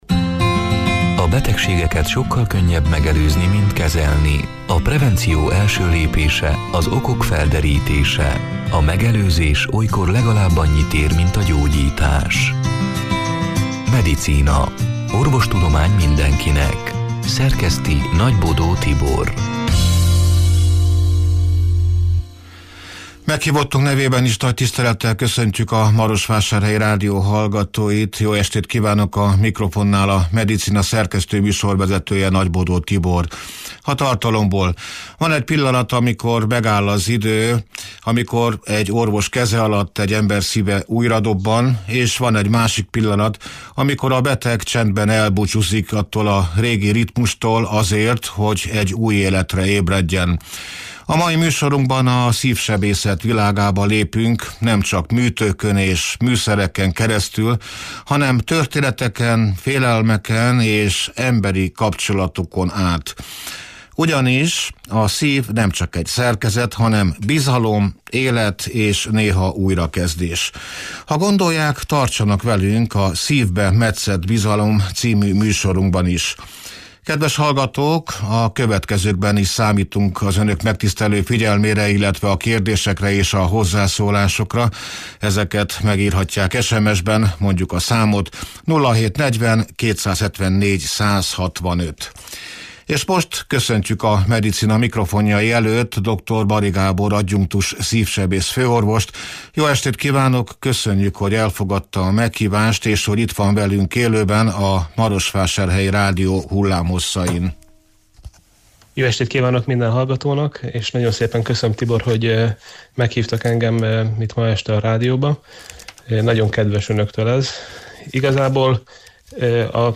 (elhangzott: 2025. április 30-án este nyolc órától élőben)